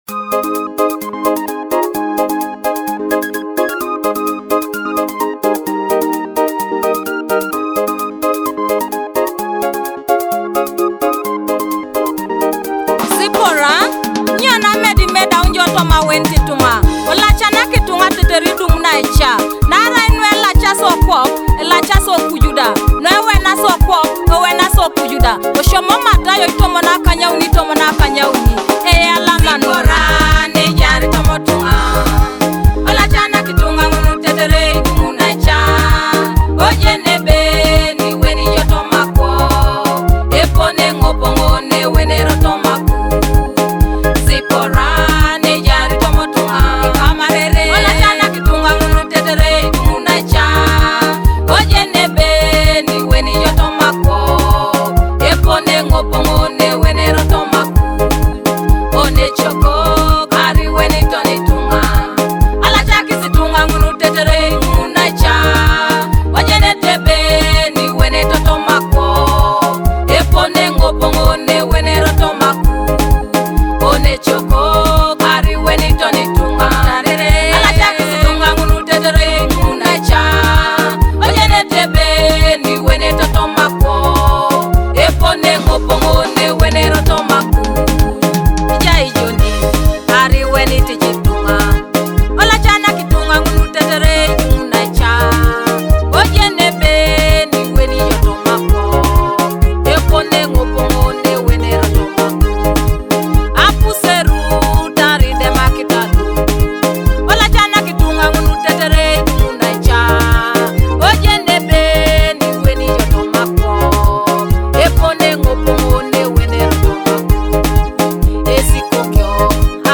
Uplifting praise and worship
a powerful Teso gospel song inspired by Matthew 18:18